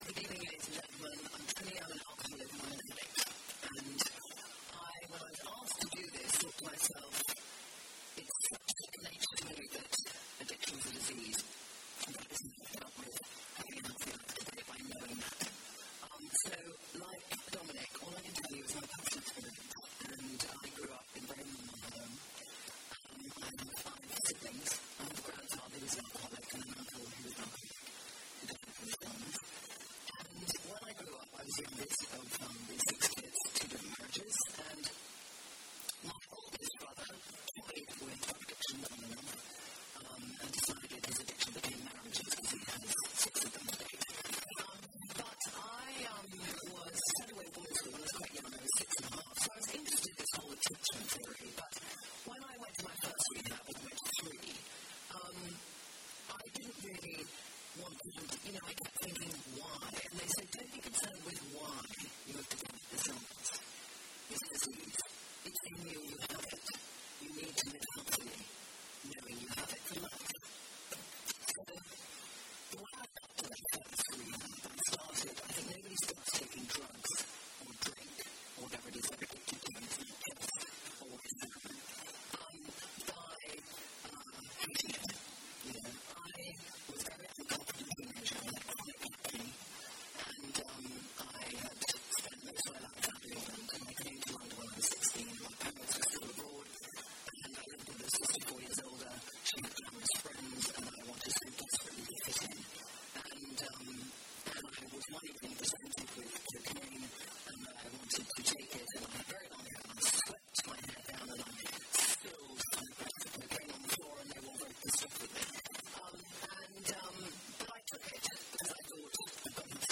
Trinny Woodall at The Spectator's addiction debate